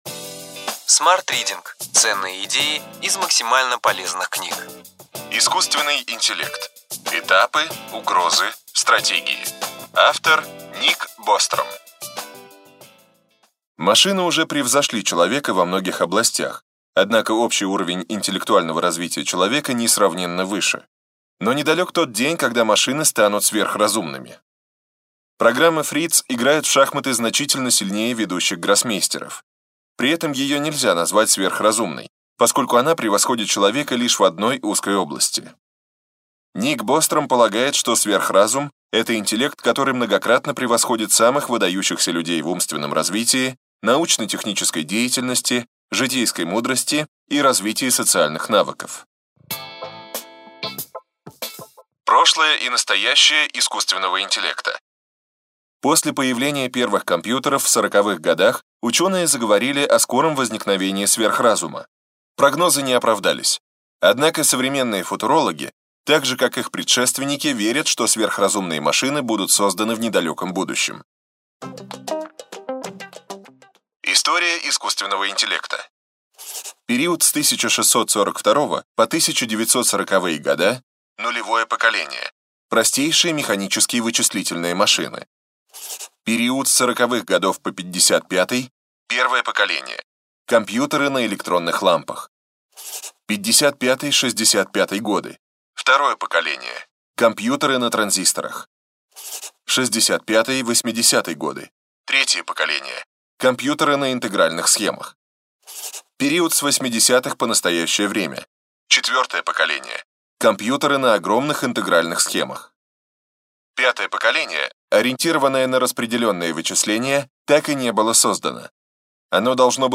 Аудиокнига Ключевые идеи книги: Искусственный интеллект. Этапы. Угрозы. Стратегии.